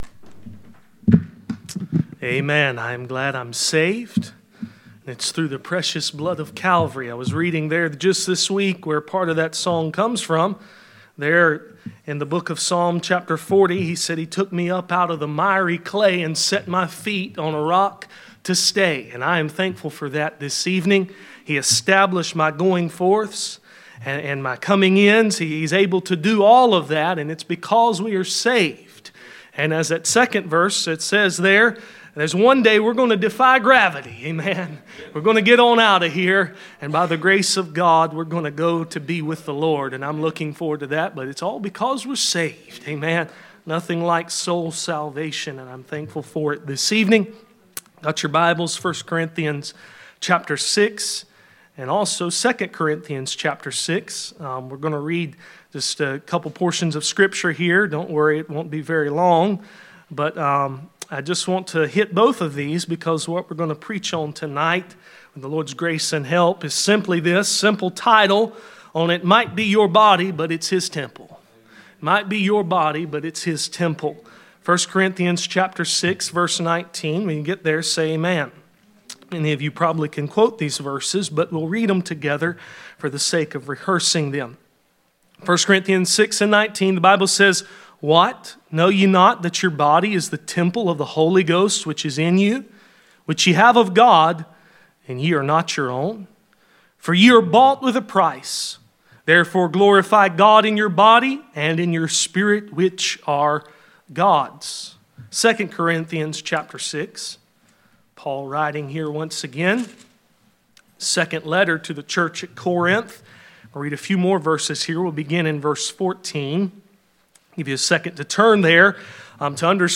Passage: 1 Corinthians 6:19-1 John 6:20, 2 Corinthians 6:14-7:1 Service Type: Sunday Evening